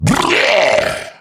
Kr_voice_kratoa_taunt03.mp3